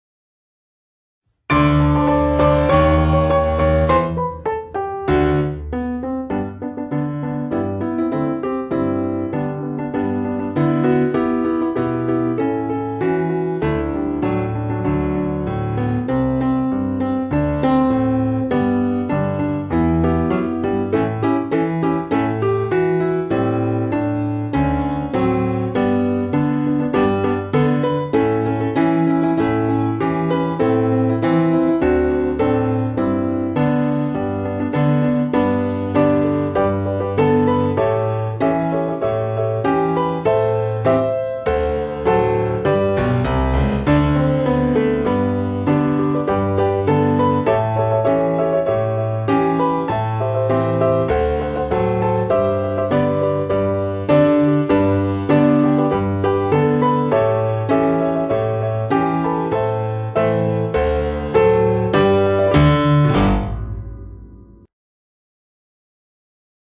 Udostępniamy podkład melodyczny do hymnu naszej szkoły.